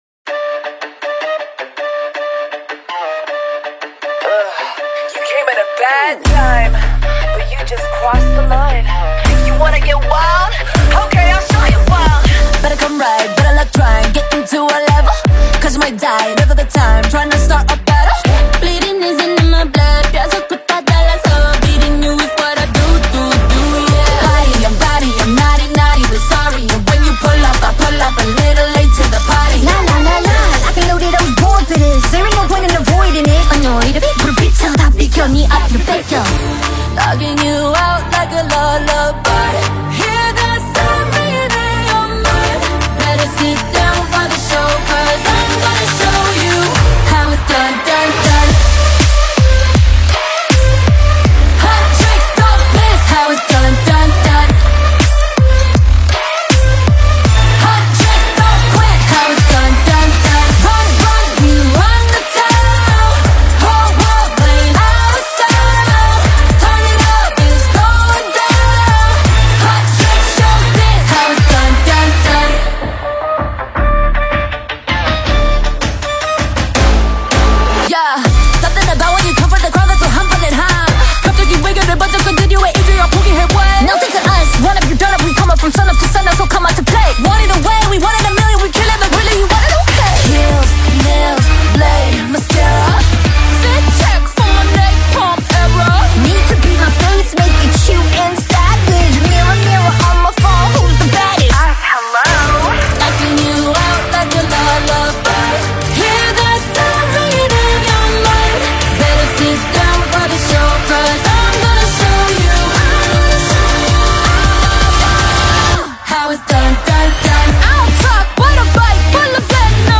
It’s a star-studded Korean song.